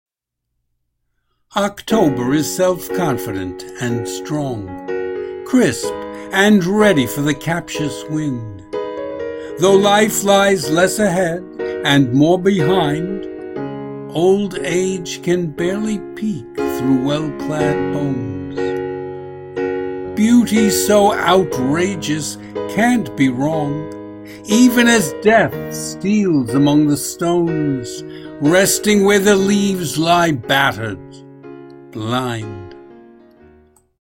Hear me read the poem as an MP3 file.
Audio and Video Music: Toccata and Fugue in D Minor.